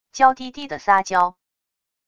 娇滴滴的撒娇wav音频